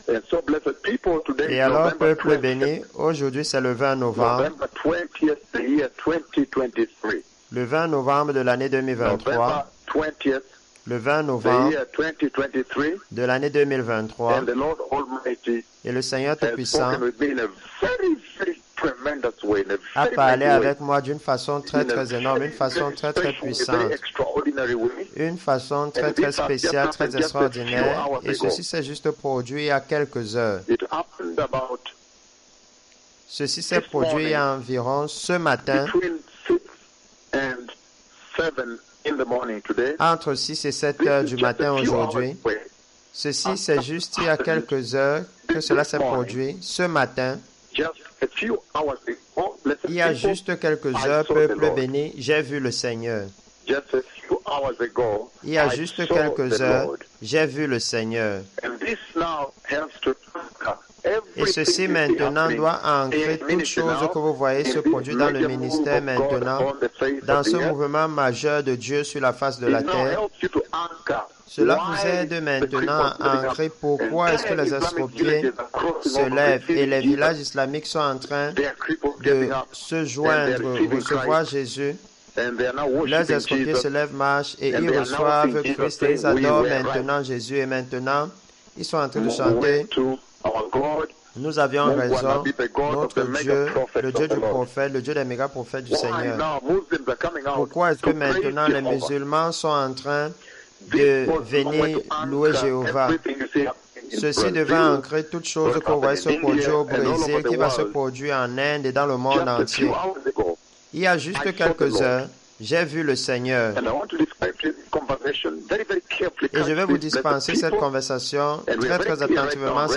Diffusion capturée depuis JESUS IS LORD RADIO.